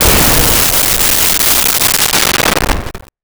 Explosion 01
Explosion 01.wav